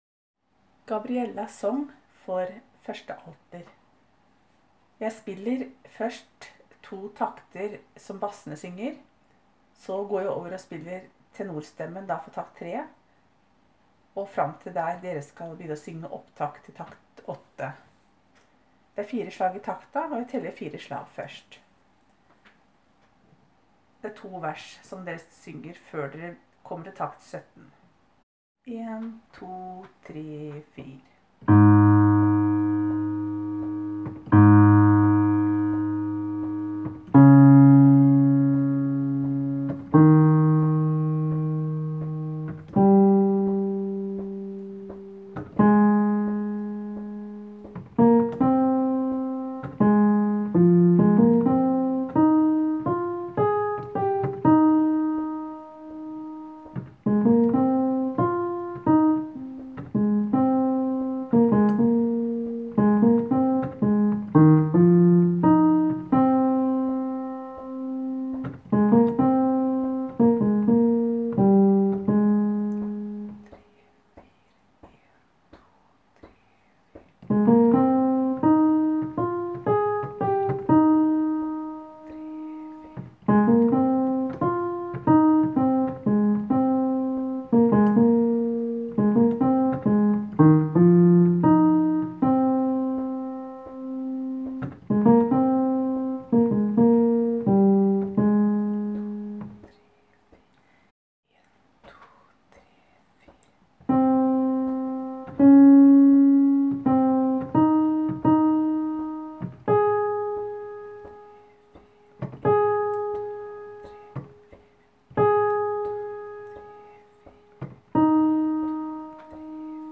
Jubileum 2019 Alter
1.Alt: